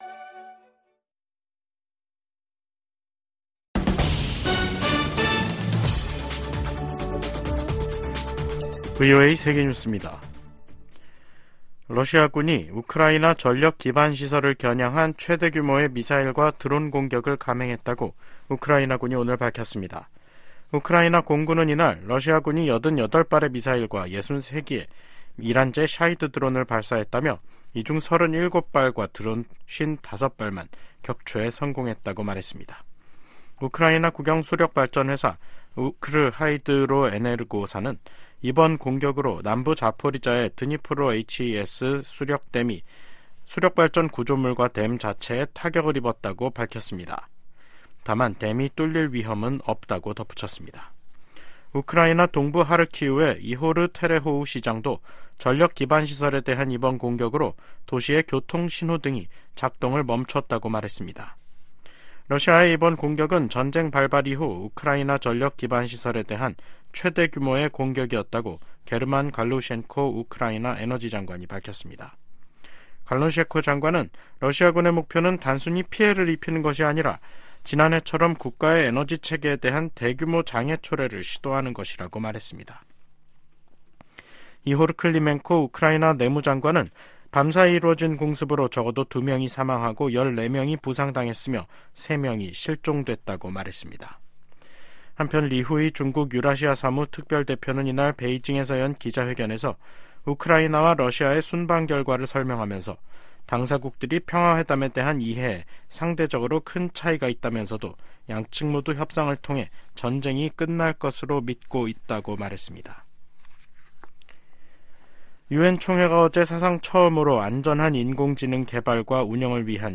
VOA 한국어 간판 뉴스 프로그램 '뉴스 투데이', 2024년 3월 22일 2부 방송입니다. 미국-한국 전시작전권 전환 작업이 완료를 위한 궤도에 있다고 폴 러캐머라 주한미군사령관이 밝혔습니다. 북한이 대륙간탄도미사일(ICBM)로 핵탄두를 미 전역에 운반할 능력을 갖췄을 것이라고 그레고리 기요 미 북부사령관 겸 북미 항공우주방위사령관이 평가했습니다. 윤석열 한국 대통령은 ‘서해 수호의 날'을 맞아, 북한이 도발하면 더 큰 대가를 치를 것이라고 경고했습니다.